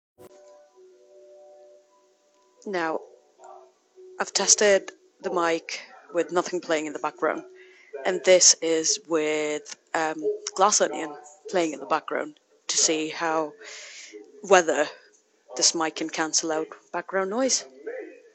Второй ролик показывает, как это звучит, когда на заднем плане воспроизводится другой медиаконтент. Я смотрел свой любимый фильм «Достать ножи: Стеклянная луковица» на большой громкости в своей маленькой гостиной. Хотя на заднем плане слышен голос Дэниела Крейга, микрофон хорошо справился с задачей уменьшить его громкость, тем самым сделав акцент на моём голосе.